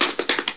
00934_Sound_walk.mp3